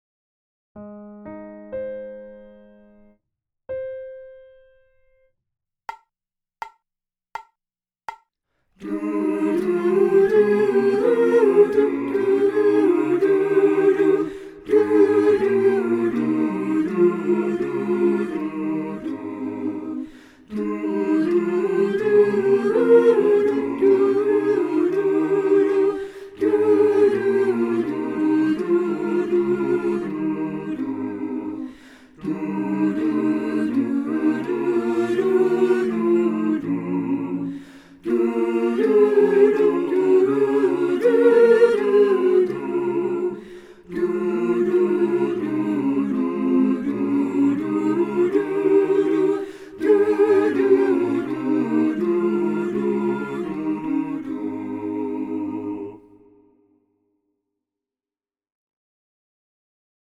Soprano
Soprano.mp3